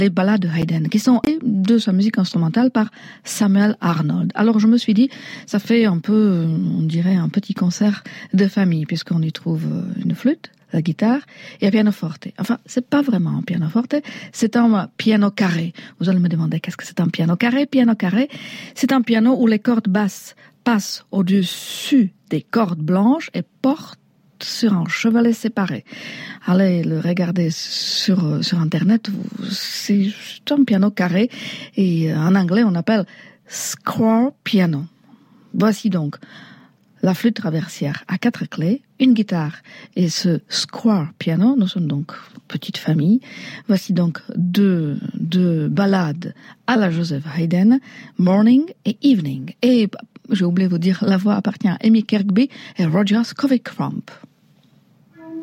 Quand la présentatrice ne sait pas de quoi elle parle...